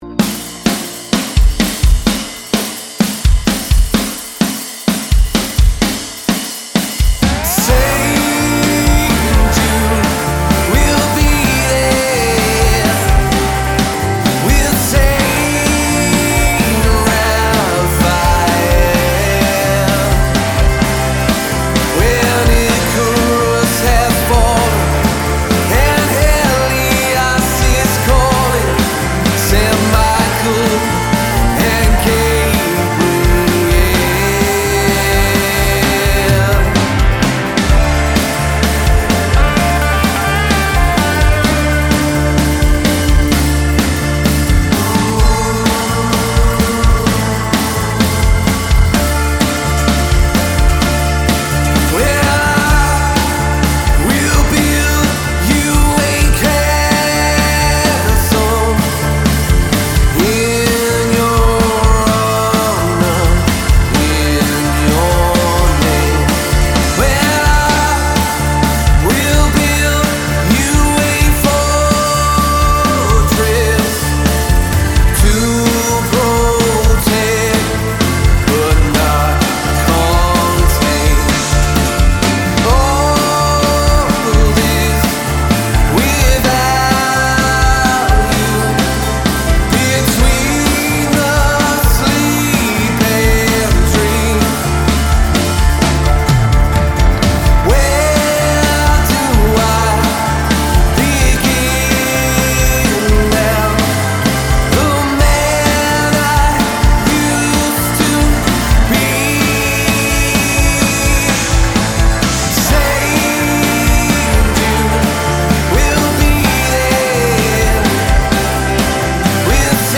Male Vocal, Guitar, Keys, Lap Steel, Bass Guitar, Drums